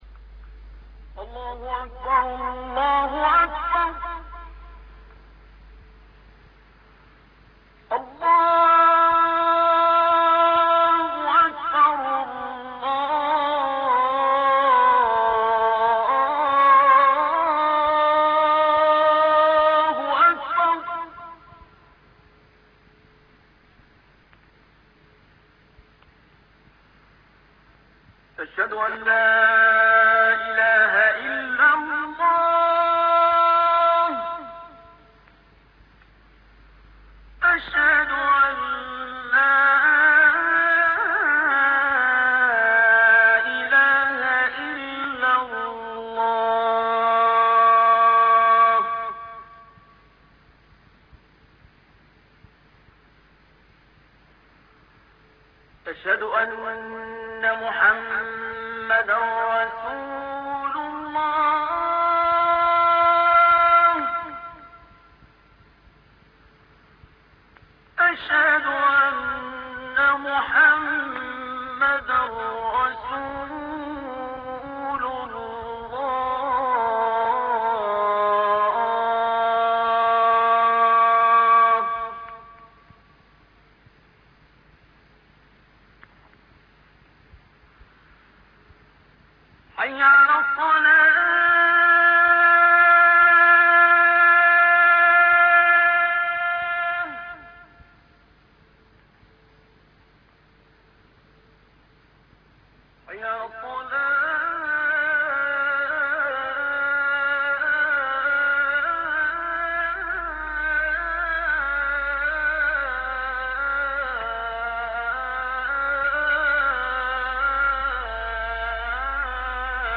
Les mélodieuses voix des muezzins s’élèvent pour appeler les croyants à la prière pour renouveler le lien avec leur Seigneur, le Très-Haut.
Dans cette page, nous vous proposons des enregistrements de l’appel à la prière effectués pour la plupart par de grands récitateurs d’Égypte.